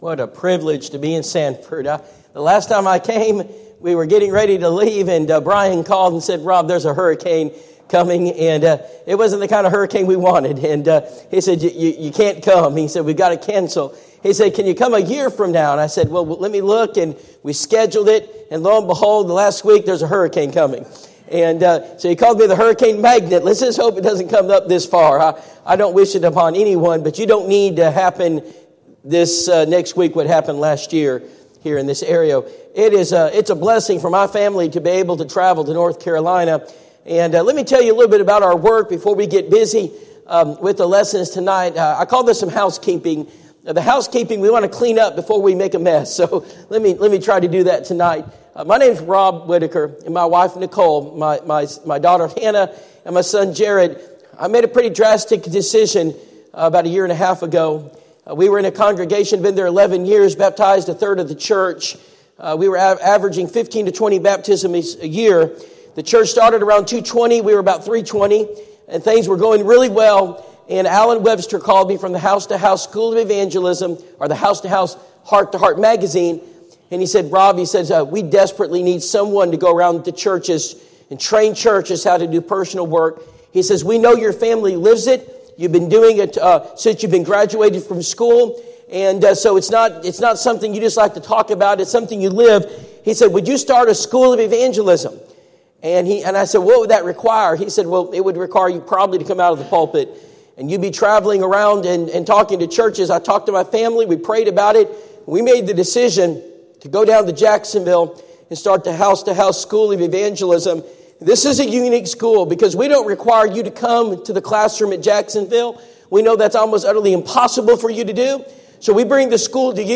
Personal Evangelism Seminar Service Type: Personal Evangelism Seminar Preacher